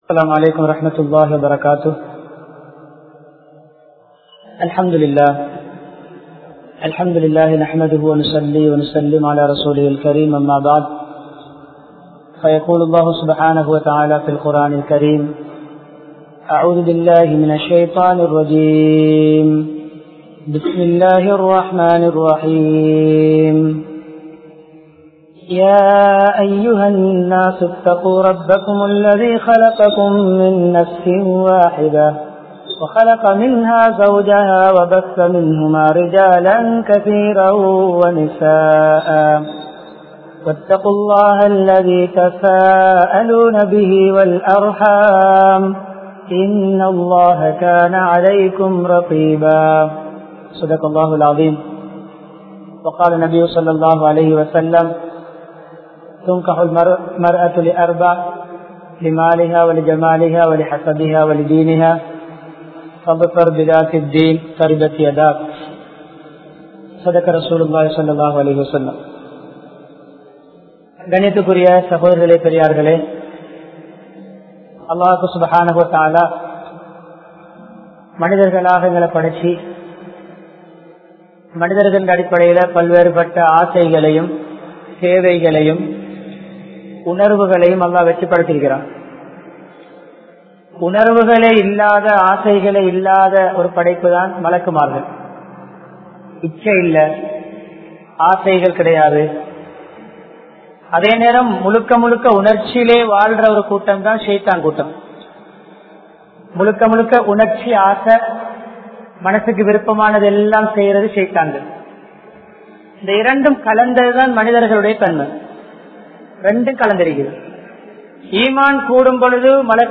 Samaathaanamaana Illara Vaalkai(சமாதானமான இல்லற வாழ்க்கை) | Audio Bayans | All Ceylon Muslim Youth Community | Addalaichenai